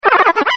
Sounds / Cries